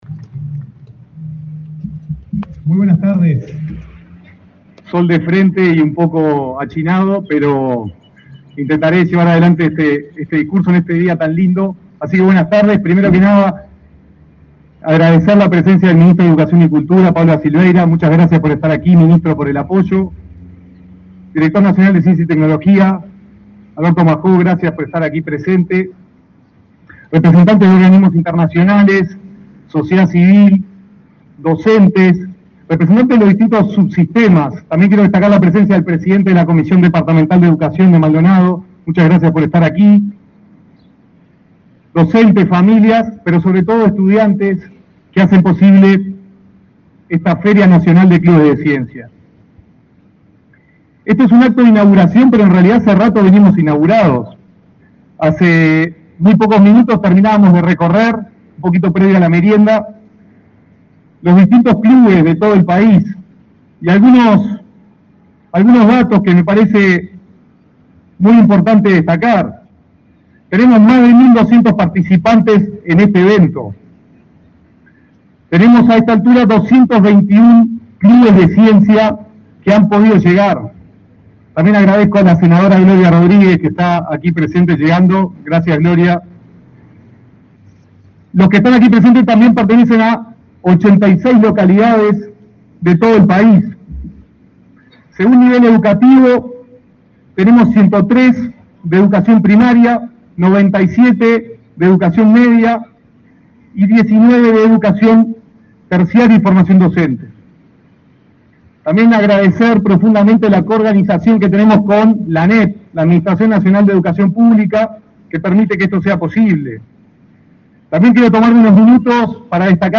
Palabras del director nacional de Educación, Gonzalo Baroni
El Ministerio de Educación y Cultura organizó la 37.ª edición de la Feria Nacional de Clubes de Ciencia, que se realiza desde el 16 al 18 de noviembre
En el evento disertó el director nacional de Educación, Gonzalo Baroni.